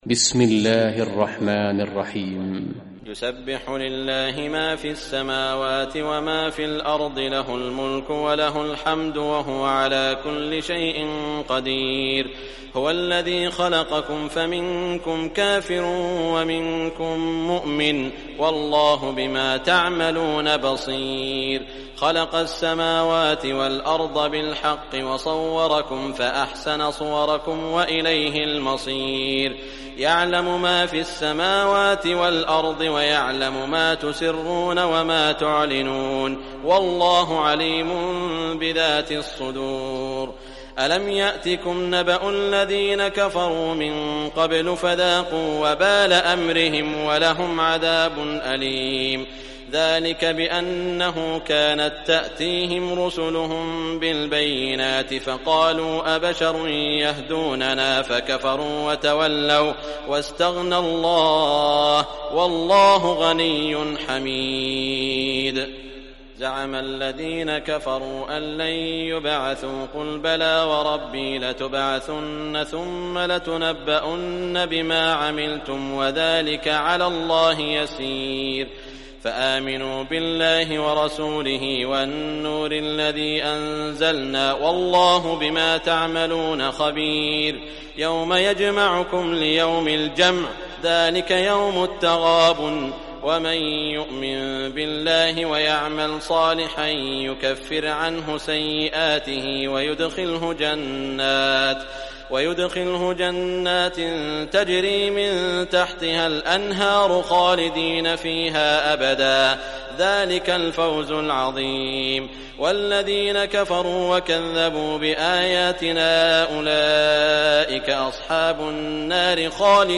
Surah At-Taghabun Recitation by Sheikh Shuraim
Surah At-Taghabun, listen or play online mp3 tilawat / recitation in Arabic in the beautiful voice of Sheikh Saud al Shuraim.